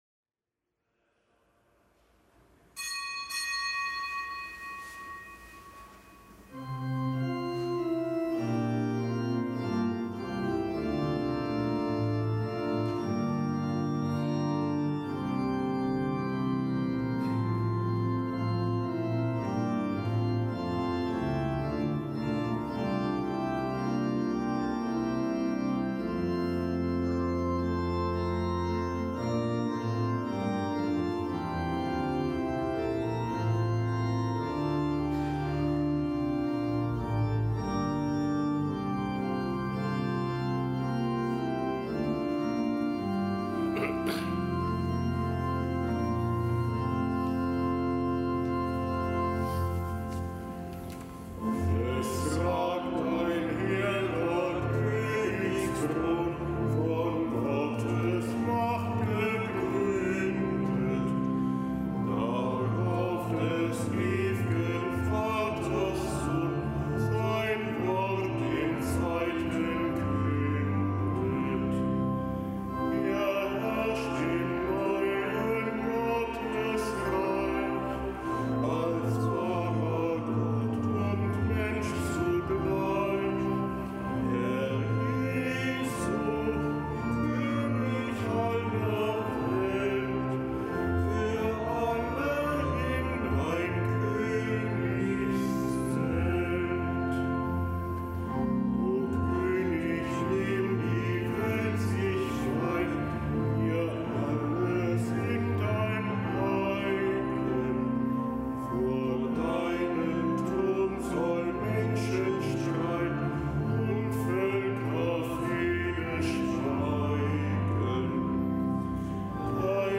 Kapitelsmesse am Dienstag der vierunddreißigsten Woche im Jahreskreis